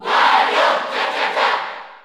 Category: Crowd cheers (SSBU) You cannot overwrite this file.
Wario_Cheer_Spanish_NTSC_SSB4_SSBU.ogg